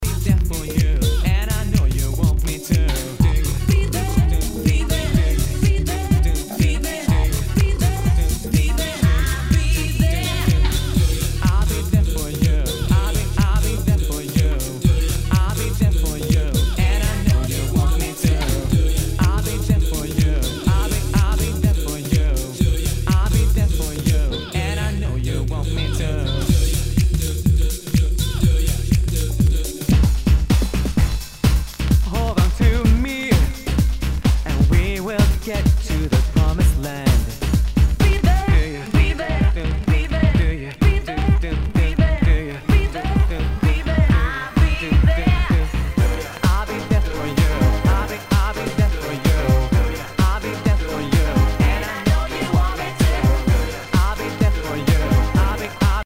HOUSE/TECHNO/ELECTRO
ナイス！ヴォーカル・ハウス・クラシック！
全体にチリノイズが入ります